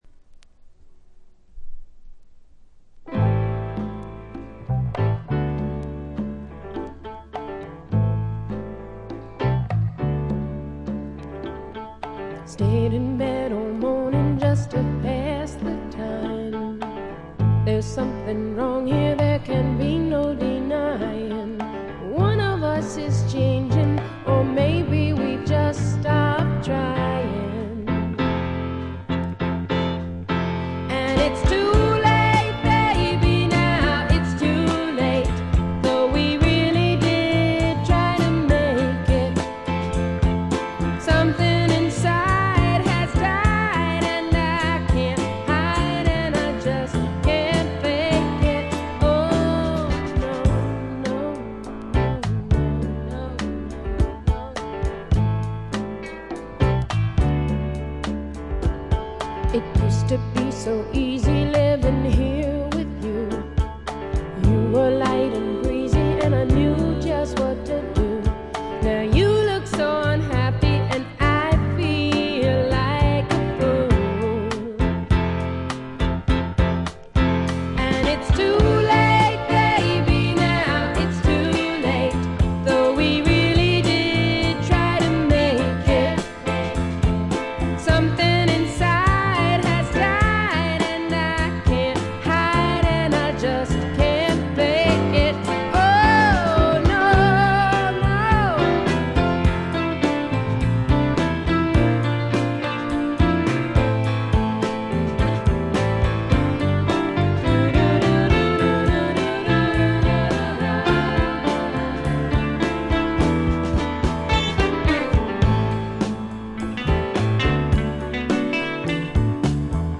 静音部で軽微なバックグラウンドノイズ、チリプチ少々。
試聴曲は現品からの取り込み音源です。